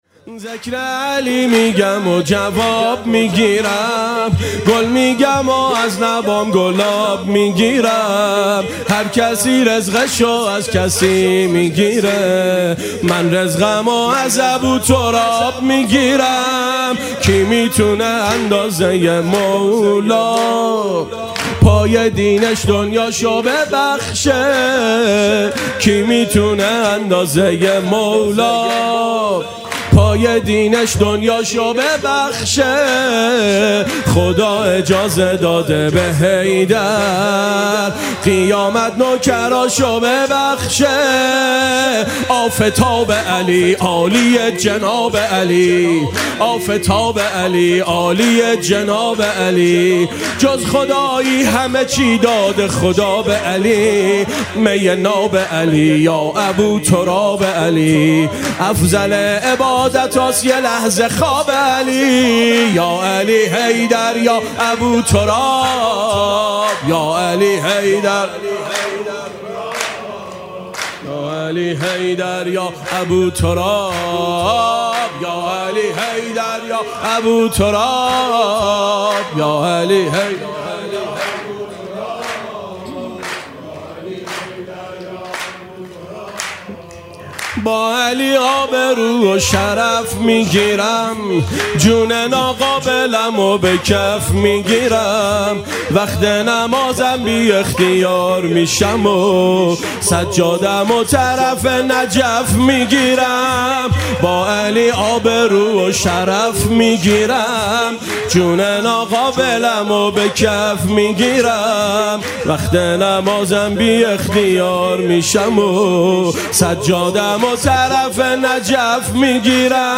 سرود
شب میلاد حضرت معصومه (س)